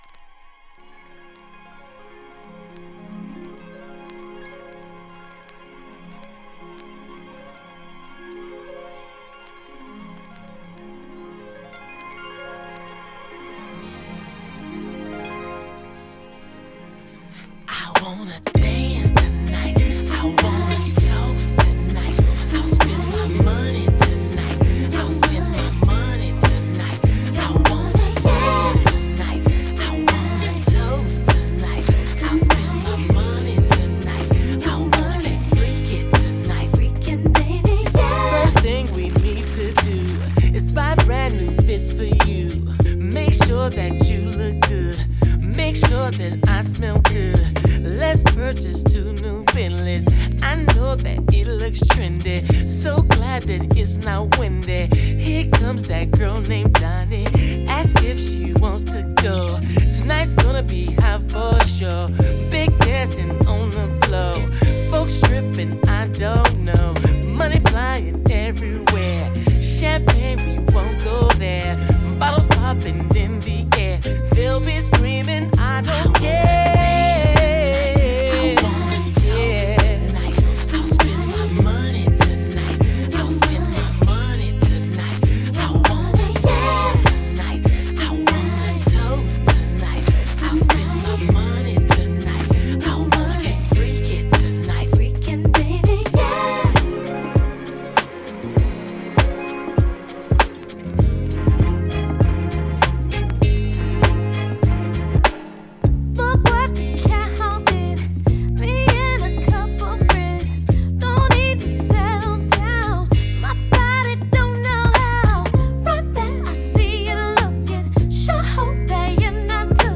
R&B名曲！